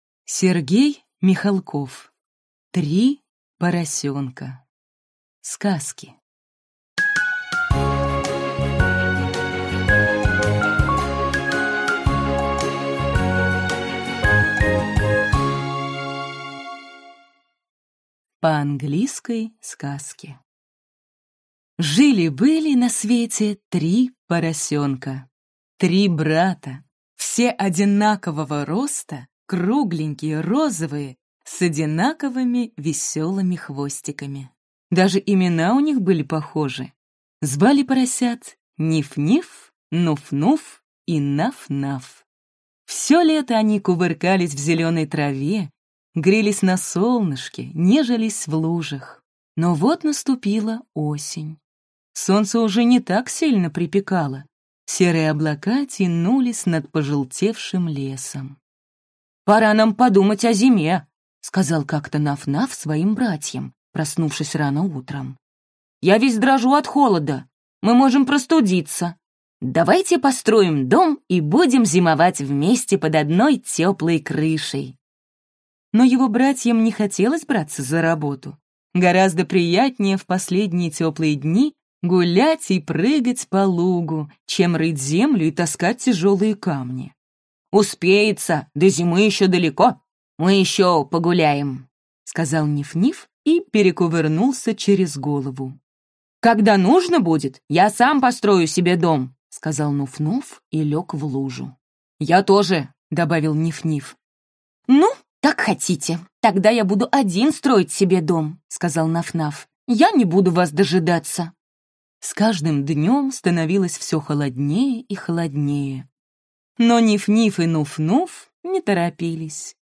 Аудиокнига Три поросёнка.